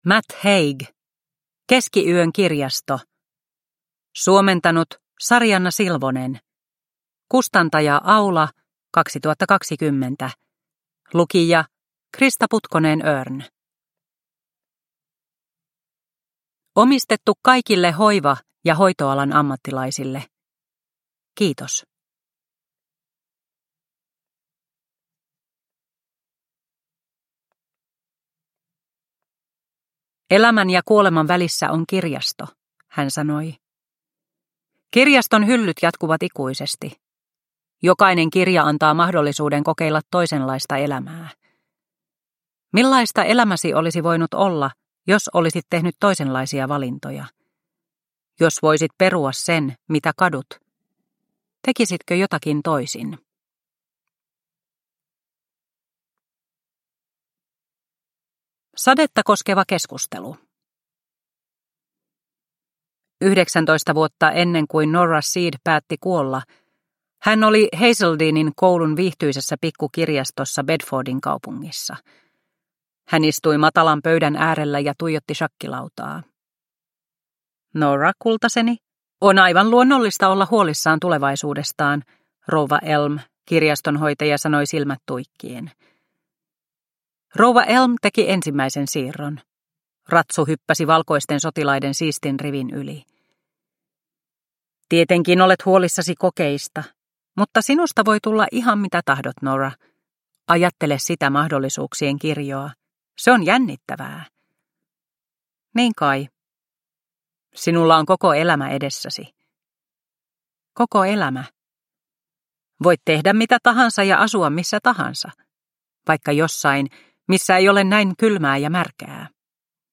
Keskiyön kirjasto / Ljudbok